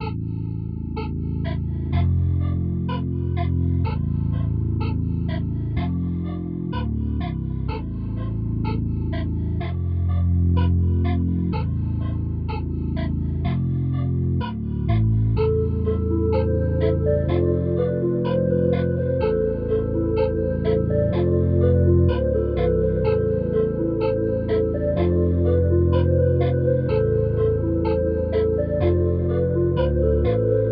标签： 125 bpm Trap Loops Pad Loops 5.17 MB wav Key : E
声道立体声